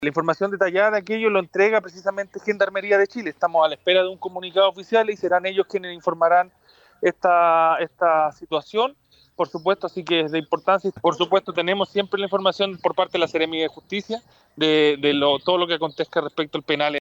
El delegado presidencial provincial de San Felipe, Daniel Muñoz, aseguró que las coordinaciones y las comunicaciones oficiales serán entregadas por Gendarmería, pero que el trabajo es realizado de manera conjunta entre las autoridades.